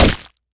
JavelinDown.mp3